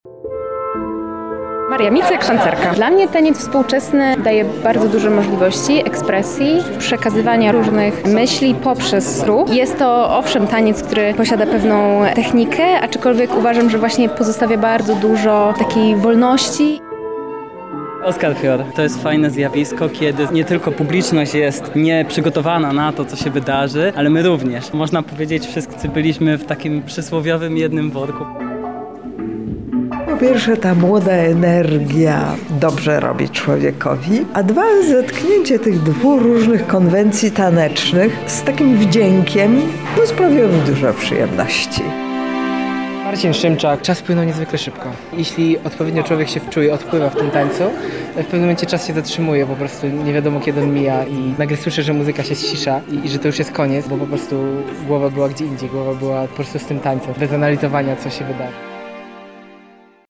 by pomówić z artystami i widzami.